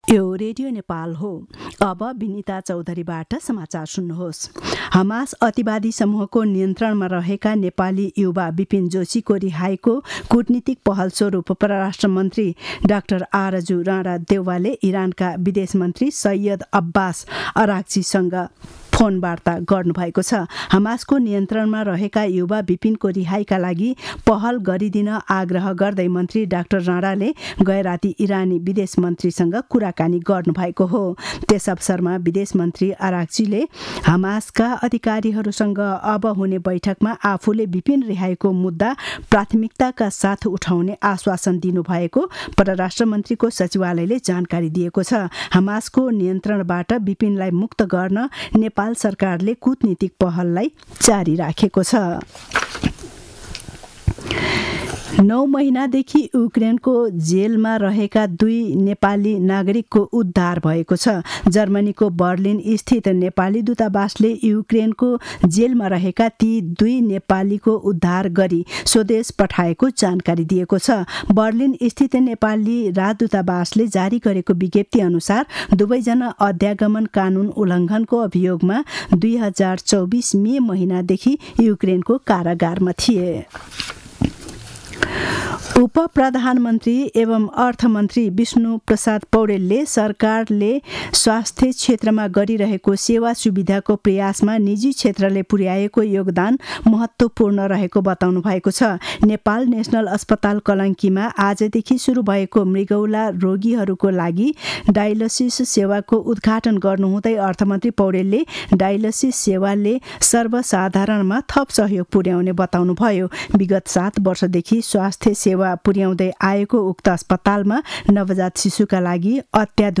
दिउँसो १ बजेको नेपाली समाचार : २६ माघ , २०८१